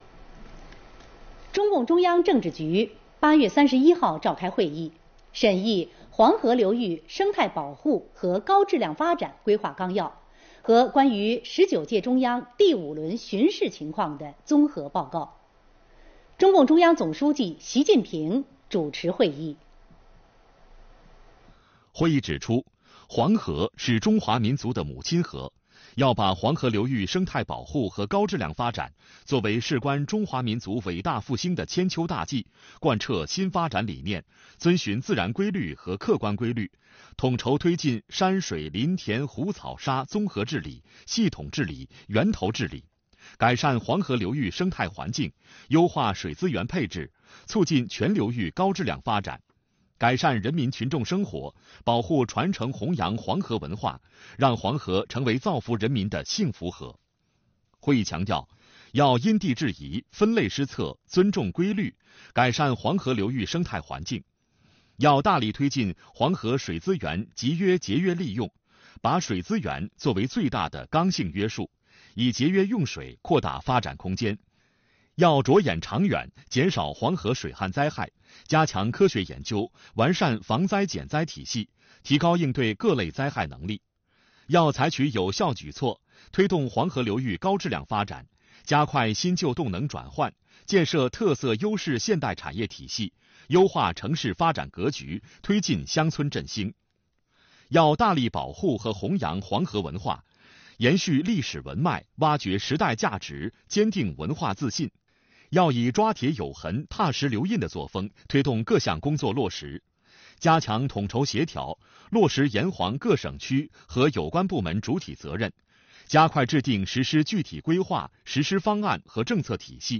视频来源：央视《新闻联播》